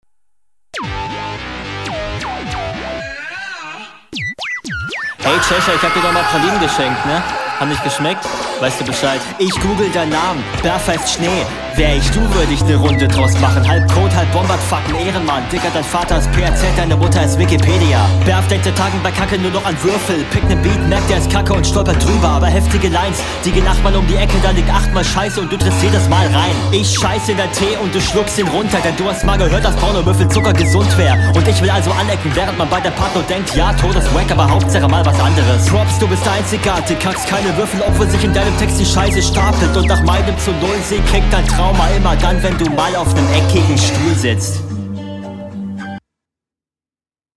Flow: Kommst auf den Beat besser als der Gegner, auch wenn der Beat immernoch sehr …
Soundqualität: Ich find die S Laute und die Mische overall besser als bei deiner HR, …